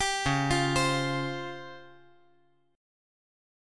C#M7b5 Chord
Listen to C#M7b5 strummed